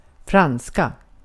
Ääntäminen
Ääntäminen : IPA: [ˈfranːˌska] Lyhenteet ja supistumat (leksikografia) fra.